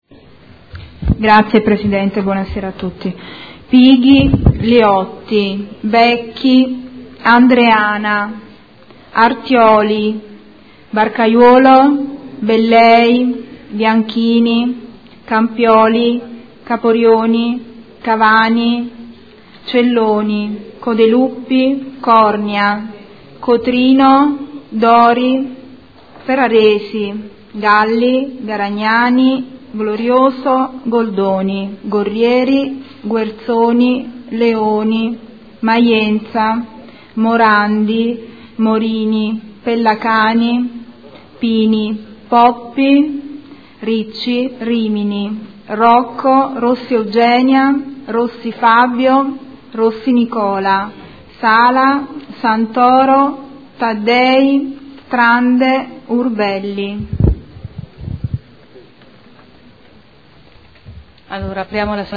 Appello.